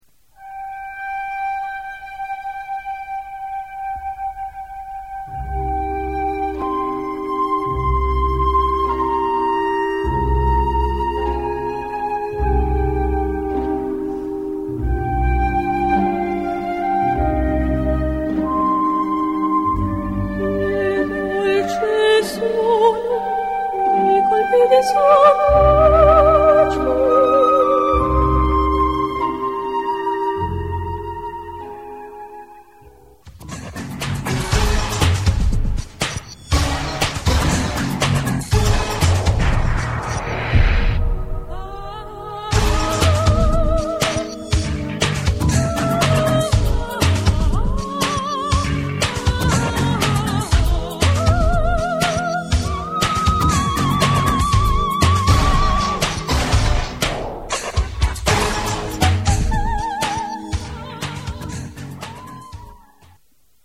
a) Instrumentation : Utilisation d'instruments électroniques